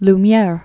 (l-myĕr, lü-), Auguste Marie Louis Nicolas 1862-1954.